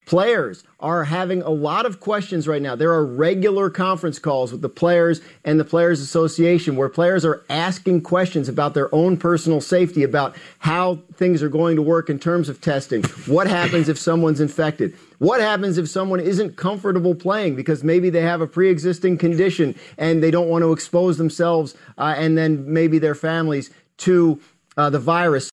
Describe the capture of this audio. (Sound from ABC Newscall)